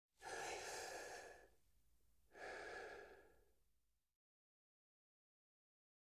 Breath; Through Mask And Air Hose